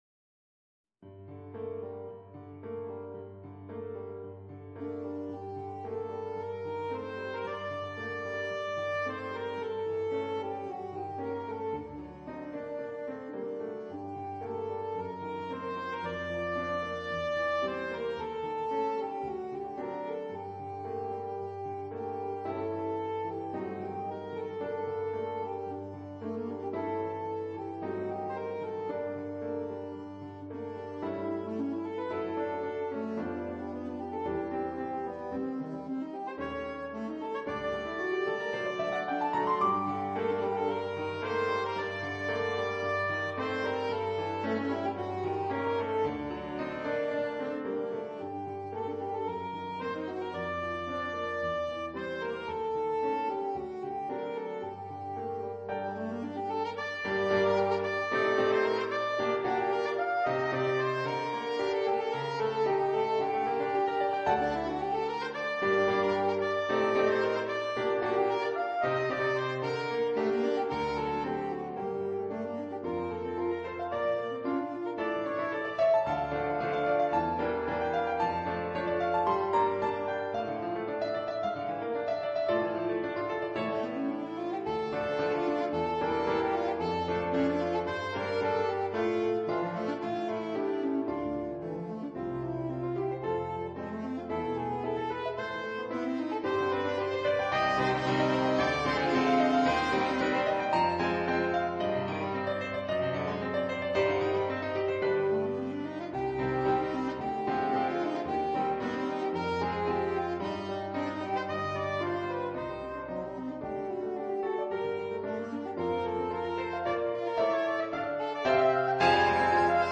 for alto sax and piano